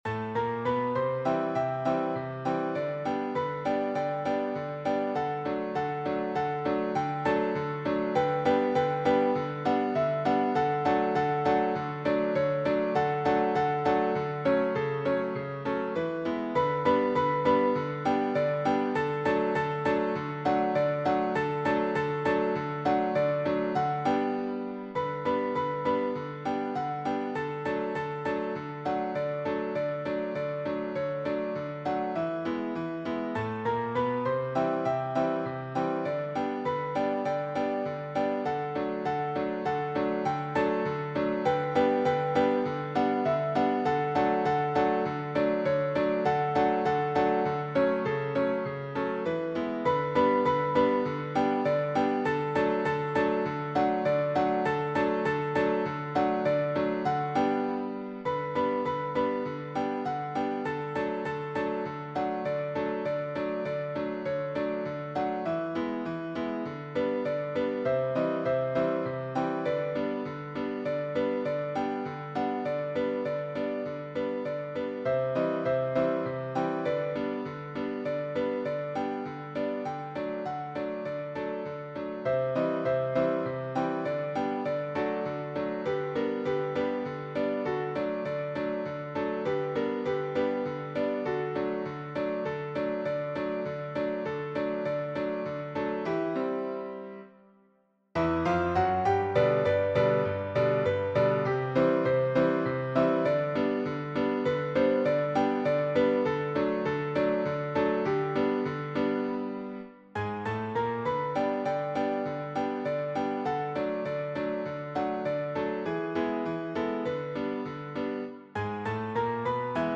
piano accompaniment
Romantic
Classic ragtime